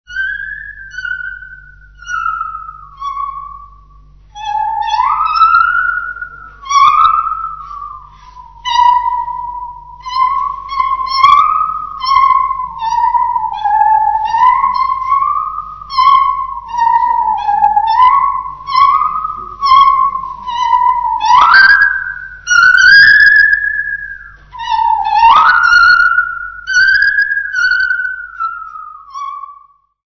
Choral music
Field recordings
Africa Eswatini Mzimpofu f-sq
sound recording-musical
Christmas Carrols.
96000Hz 24Bit Stereo